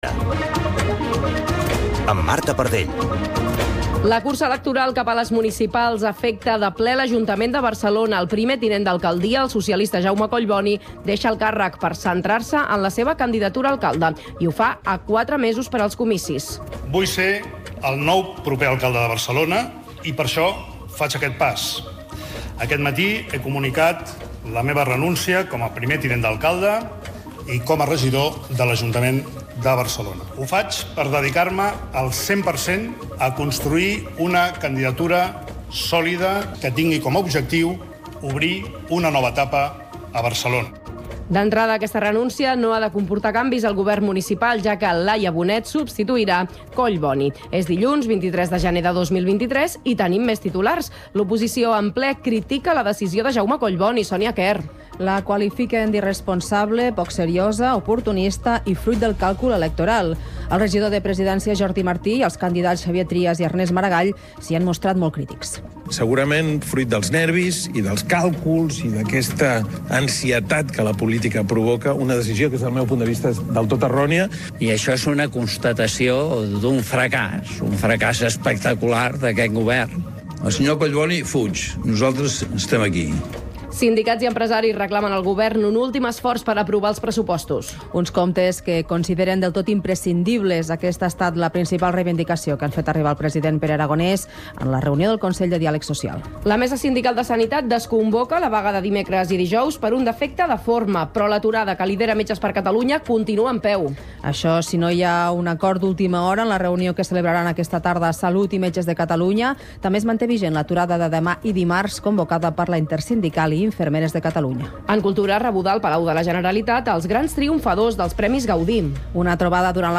Informatiu radiofònic que dóna prioritat a l’actualitat local.